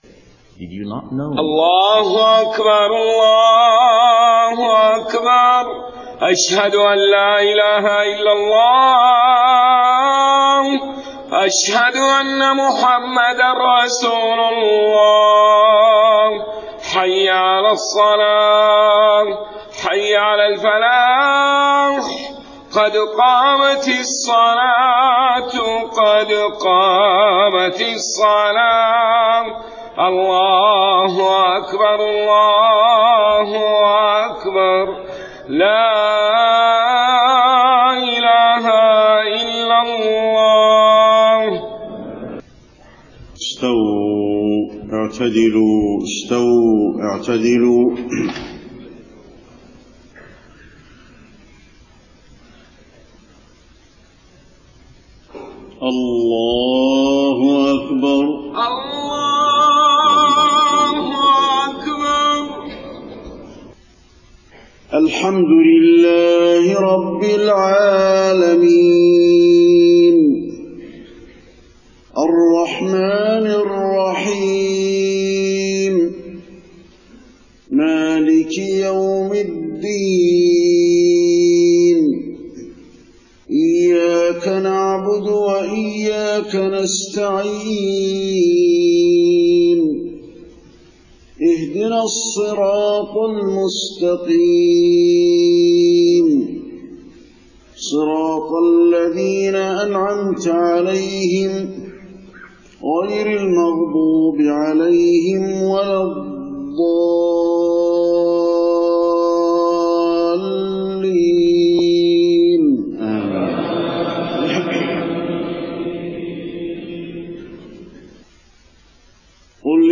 صلاة الفجر 19 صفر 1431هـ خواتيم سورة الزمر 53-75 > 1431 🕌 > الفروض - تلاوات الحرمين